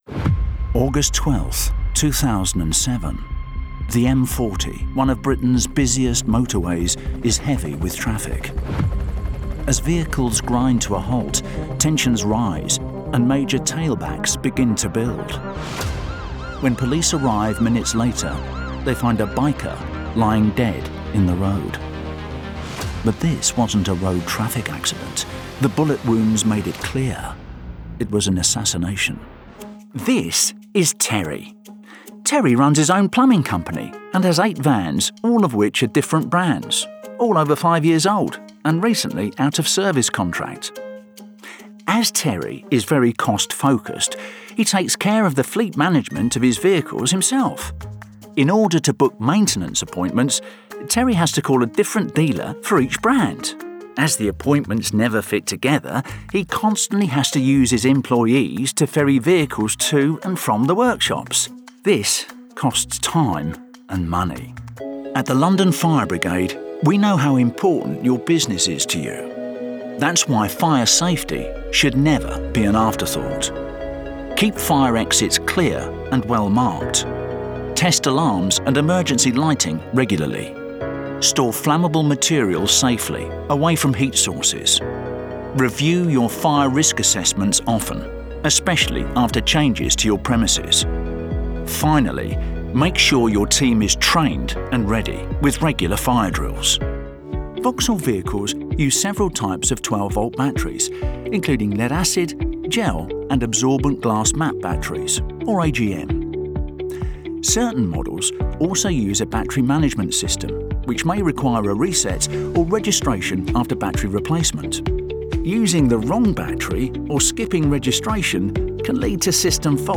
90 Second SoundBite
Male
Estuary English
Gravelly
Warm